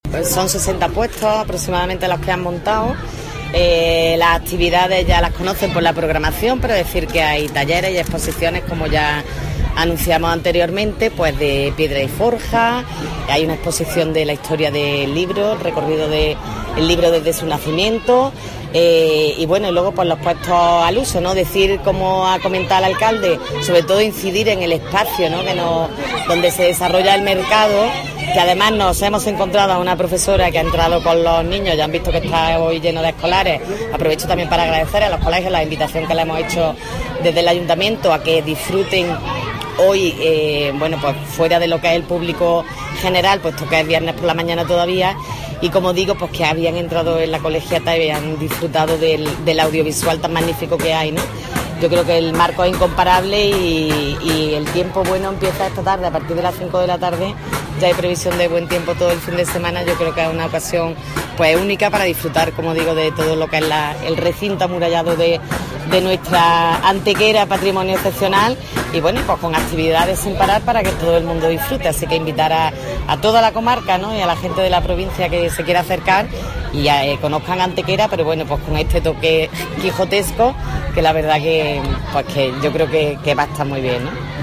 La teniente de alcalde Belén Jiménez ha recordado durante la visita de este mediodía también que habrá un servicio de transporte especial cada hora con microbús eléctrico al precio de 1 euro para facilitar el acceso a las personas que lo deseen estableciéndose como puntos de subida y bajada de viajeros la plaza de Castilla, la plaza de San Sebastián y el mirador de las Almenillas (viernes de 18:00 a 23:00 horas, sábado de 11:00 a 15:00 y de 17:00 a 23:00 horas, domingo de 11:00 a 15:00 y de 17:00 a 22:00 horas). Cortes de voz B. Jiménez 579.07 kb Formato: mp3